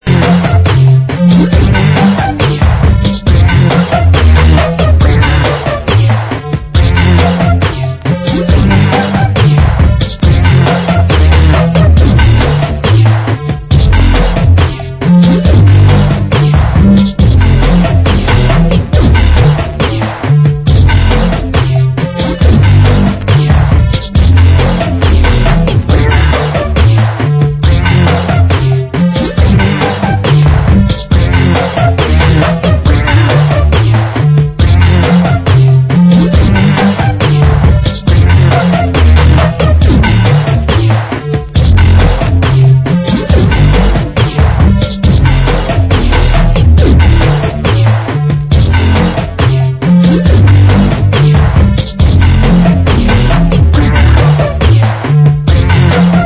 Genre: Grime